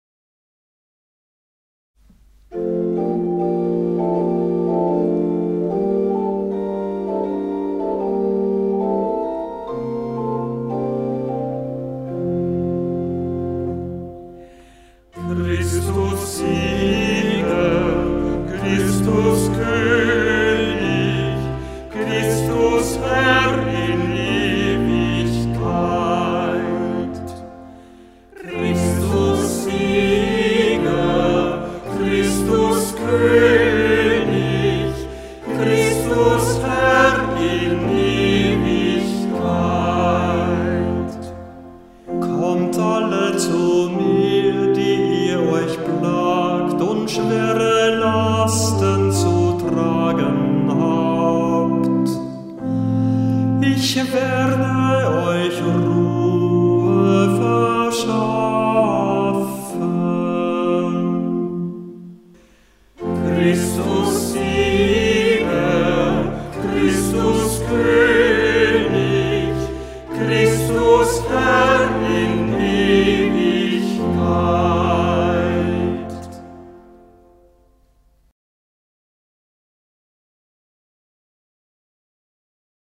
Ruf vor dem Evangelium - November 2025
Hörbeispiele aus dem Halleluja-Büchlein
Kantor wenn nicht anders angegeben